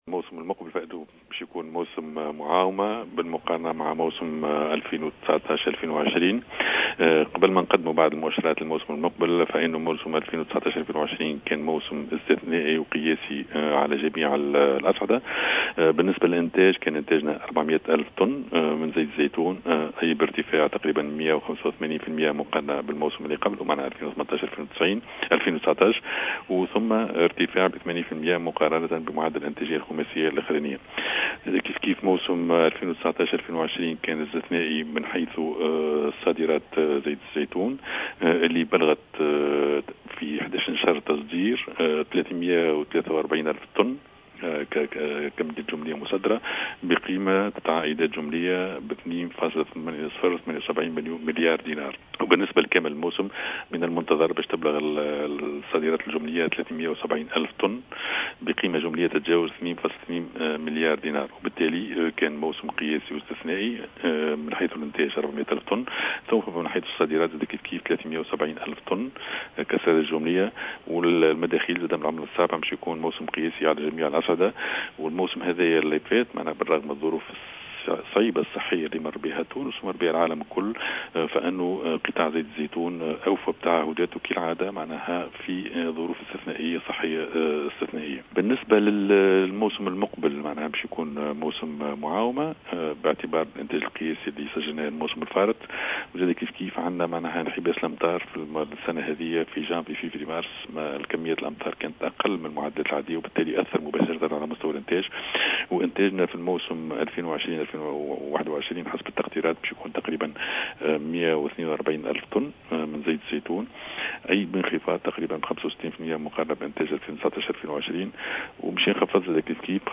وتوقع بيوض في تصريح للجوهرة أف أم، انخفاض مداخيل القطاع من العُملة الصعبة، حيث من المقرر تصدير 120 ألف طن من زيت الزيتون فقط، مسجلا بذلك انخفاضا كبيرا جدّا مقارنة بالموسم الفارط الذي كان قياسيا على جميع الأصعدة، وفق تعبيره.